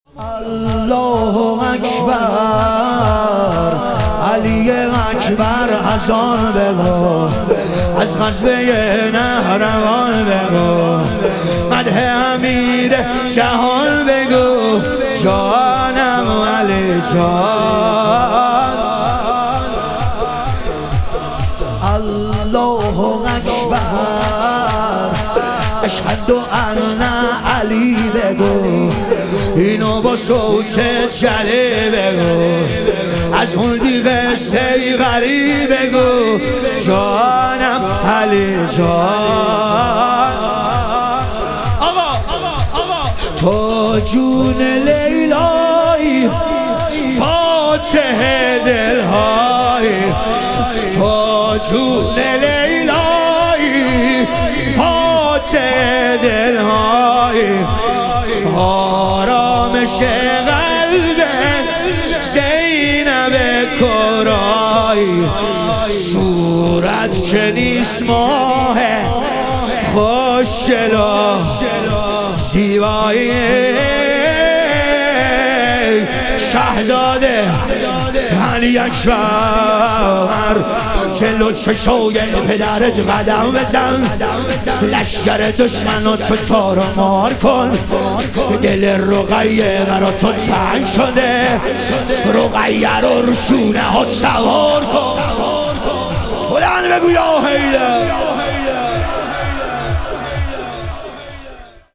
شهادت حضرت جعفرطیار علیه السلام - شور